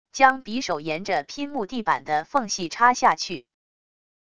将匕首沿着拼木地板的缝隙插下去wav音频